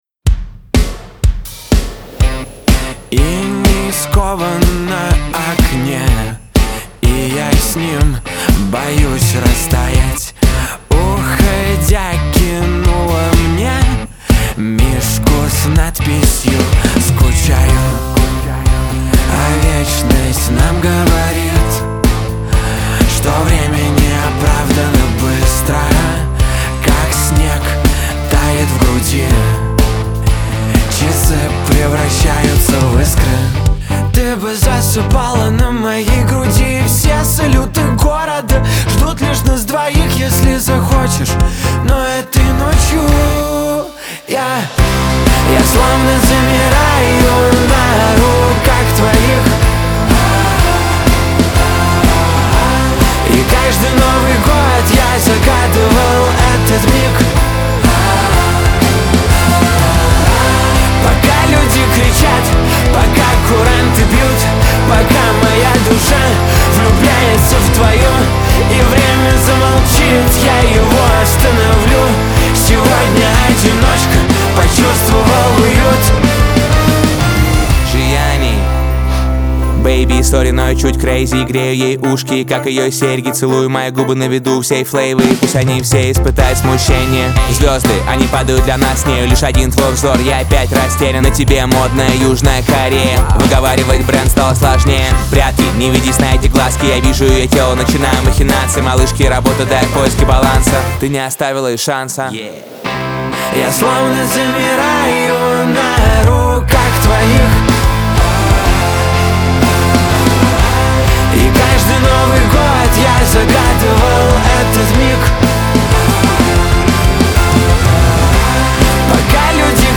pop
диско
эстрада